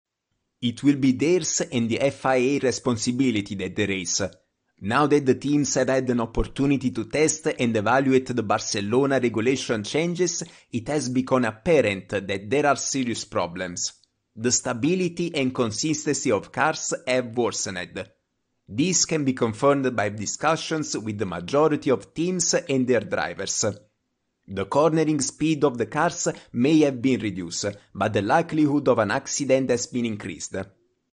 当前位置：首页> 样音试听 >优选合集 >外语配音合集 >小语种配音